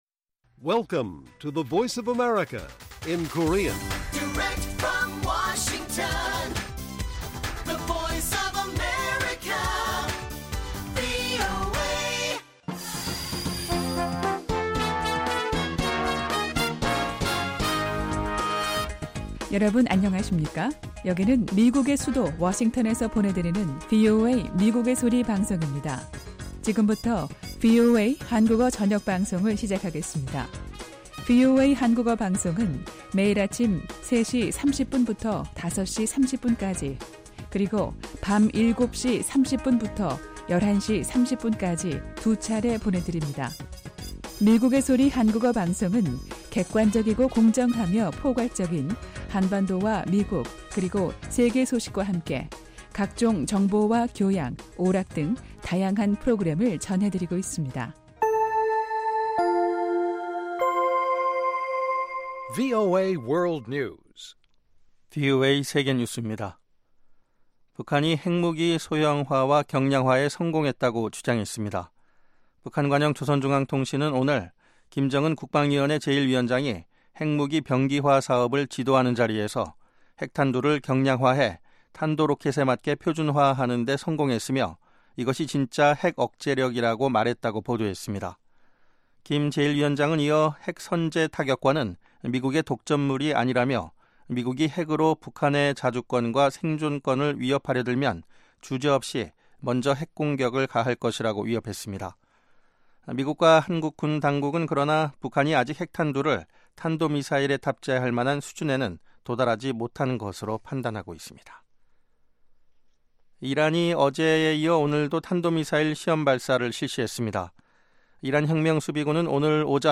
VOA 한국어 방송의 간판 뉴스 프로그램 '뉴스 투데이' 1부입니다.